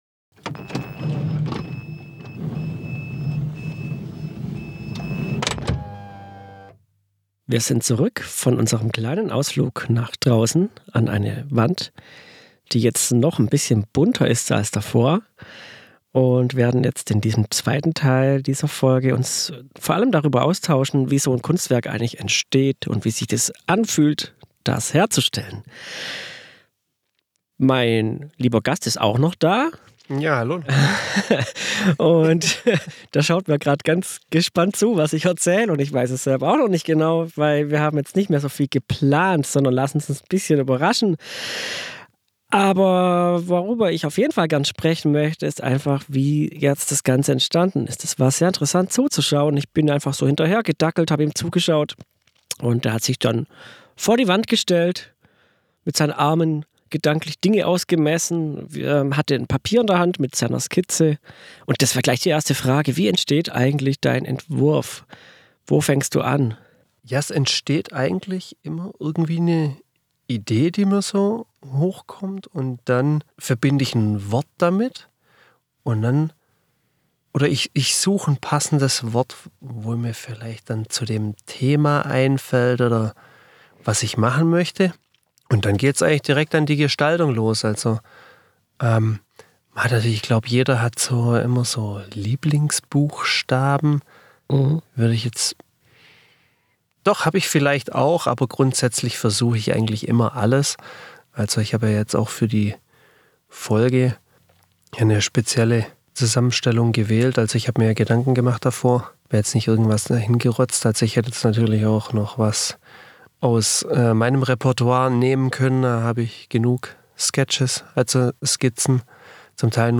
Zurück im Bus beleuchten wir den Weg eines Graffitis genauer: von der Idee zur Skizze, durch die Dose, an die Wand. Warum das viel einfacher klingt, als es ist und welche Faszination es ausübt erfährst du hier.